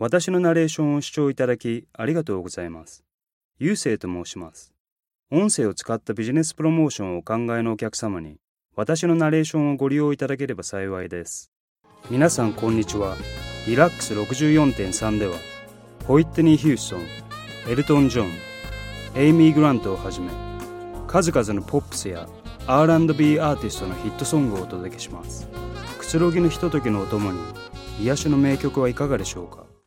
Japanese, Male, 30s-40s